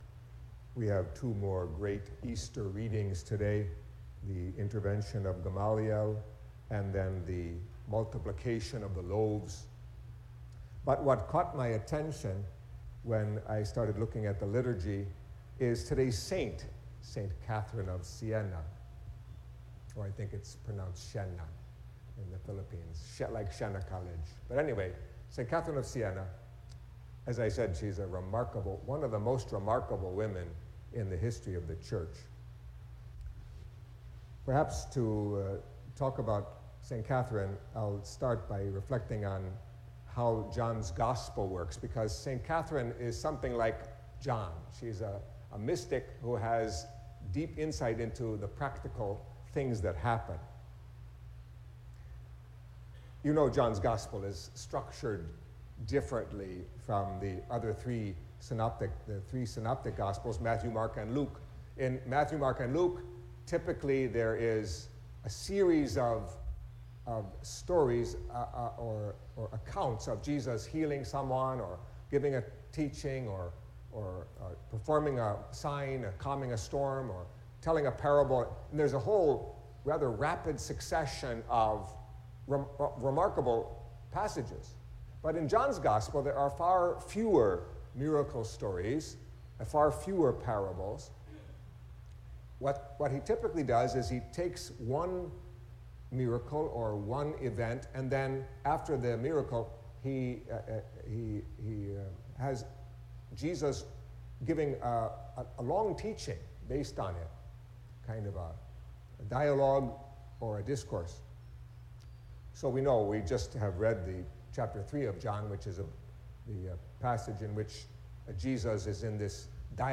Catholic Mass homily for Friday of the Second Week of Easter